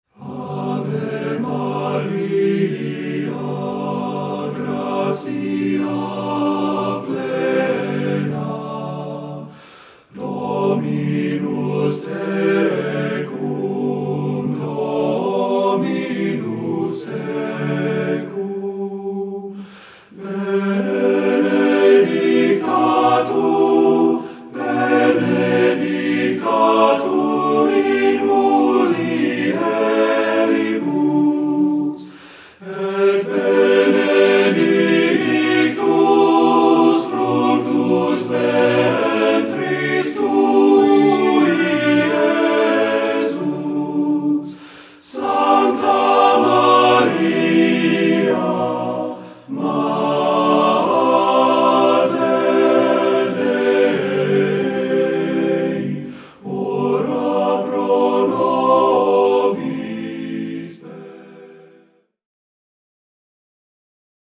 Voicing: TTBB